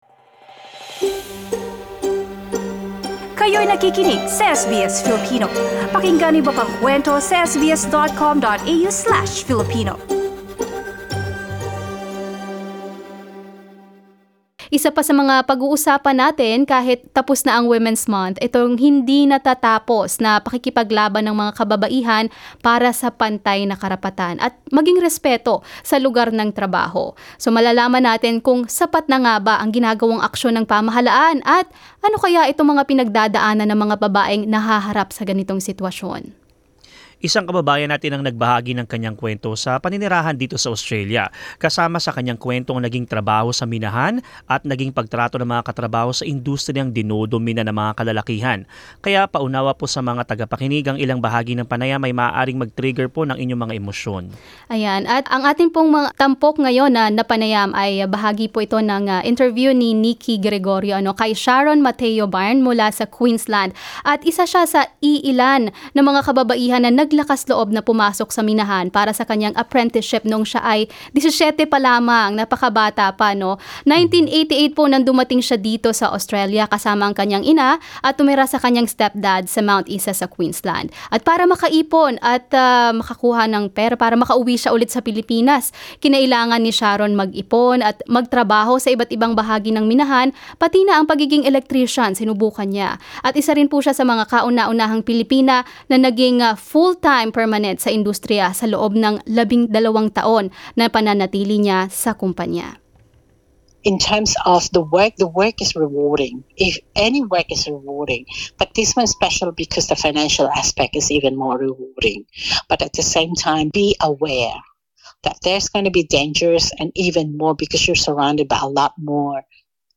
Pakinggan ang pag-uusap ukol sa sitwasyon ng mga babaeng miners sa mga minahan sa Australya.